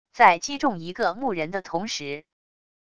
在击中一个木人的同时wav音频